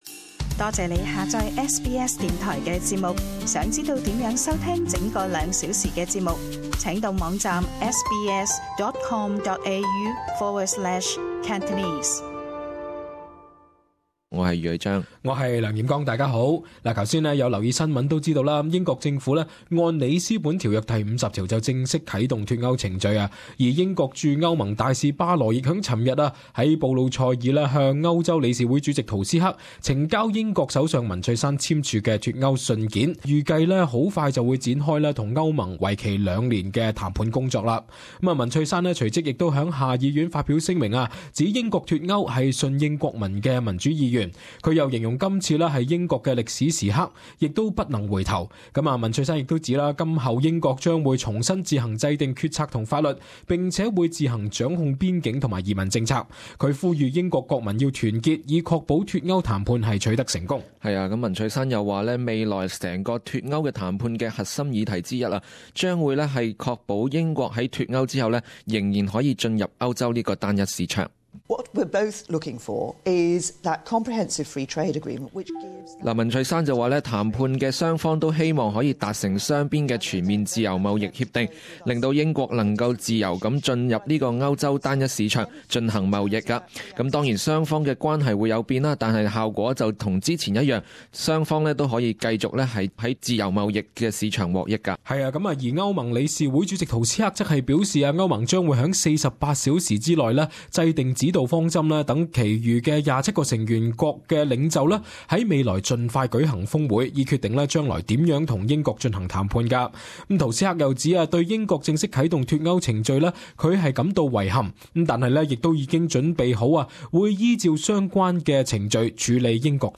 【時事報導】英國正式啟動脫歐程序